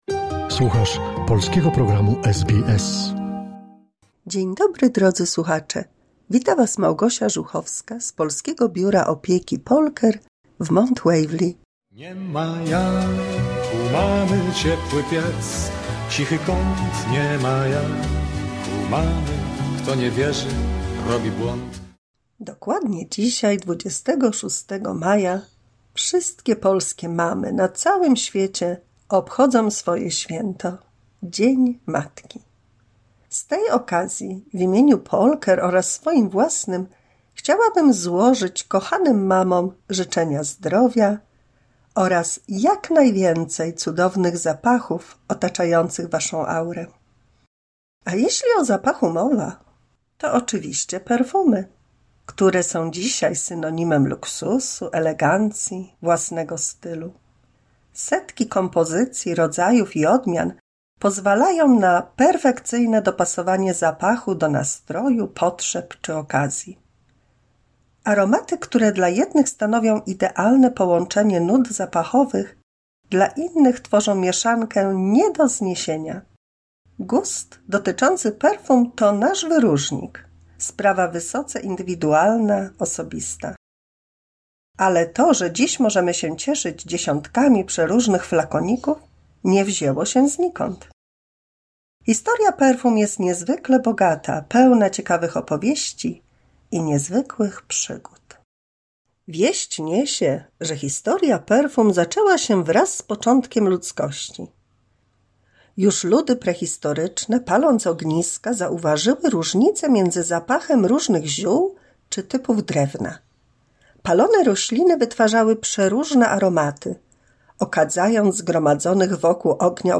155 mini słuchowisko dla polskich seniorów